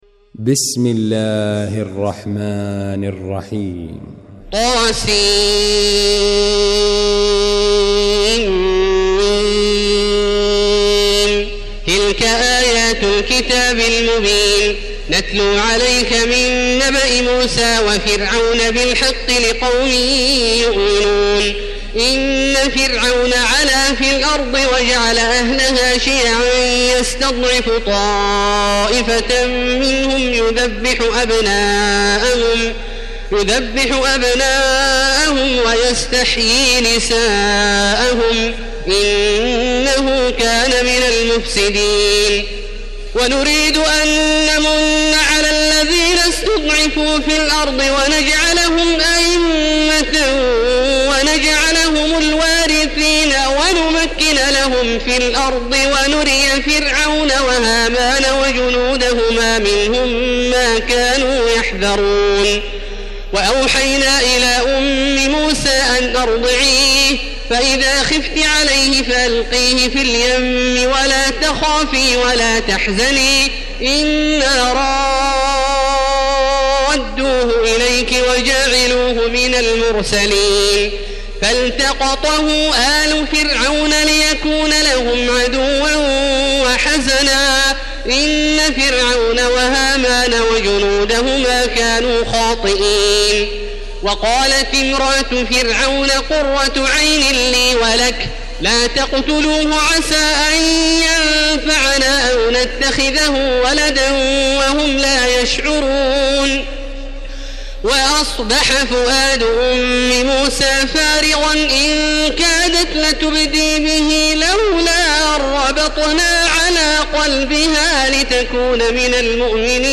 المكان: المسجد الحرام الشيخ: فضيلة الشيخ عبدالله الجهني فضيلة الشيخ عبدالله الجهني فضيلة الشيخ ماهر المعيقلي القصص